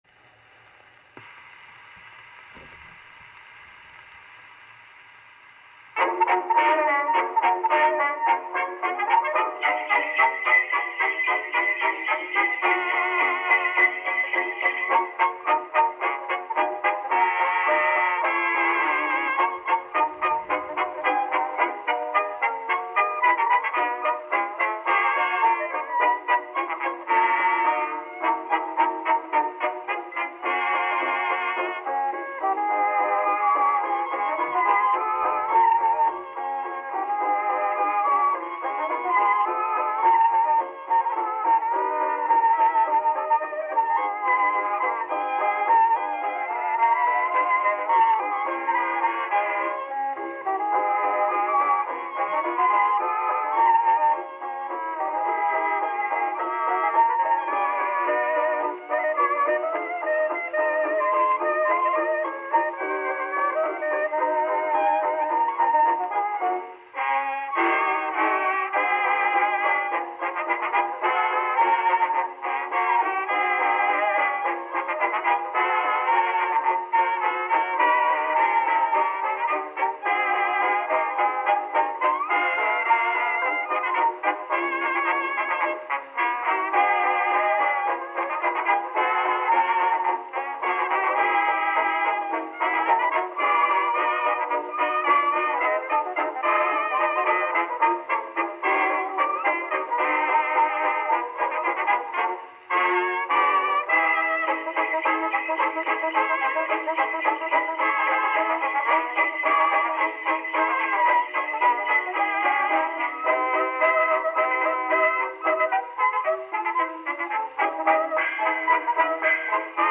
[mit Deklamation, Harmoniumbegleitung und Kirchenglocken.]